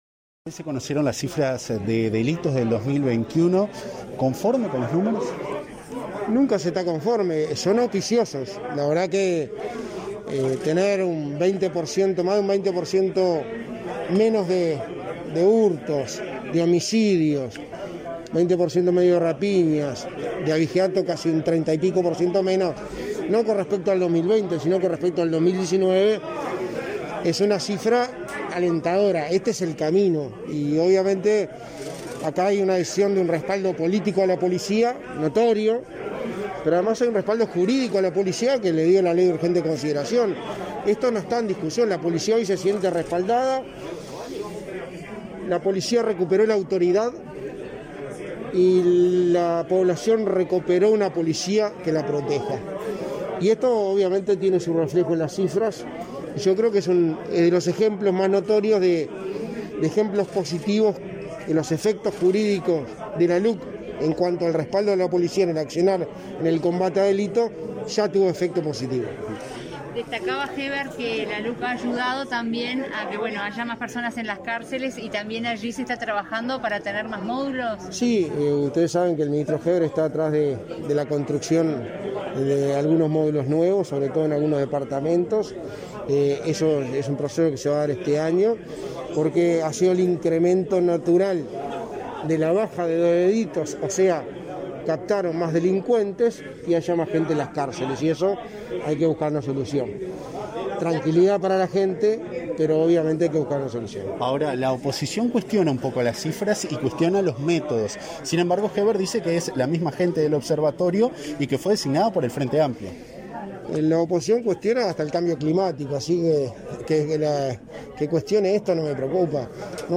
Declaraciones a la prensa del secretario de la Presidencia, Álvaro Delgado